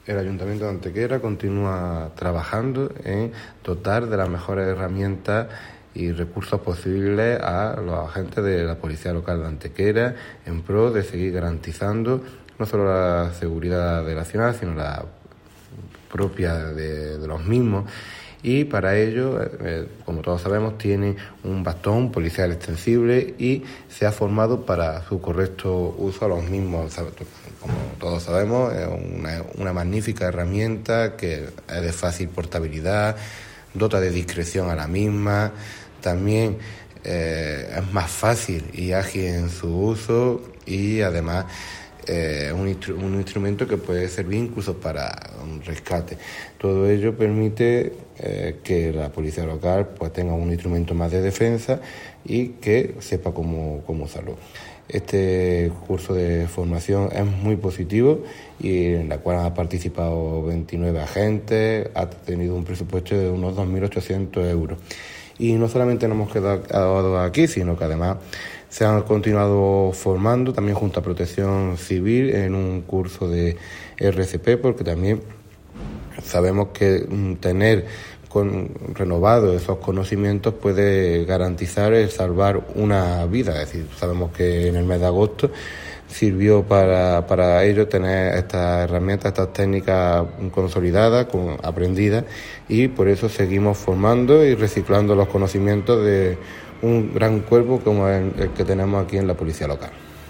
El teniente de alcalde delegado de Seguridad y Protección Civil, Antonio García Mendoza, informa del desarrollo de dos nuevas acciones de carácter formativo dirigidas a agentes de la Policía Local e integrantes de Protección Civil.
Cortes de voz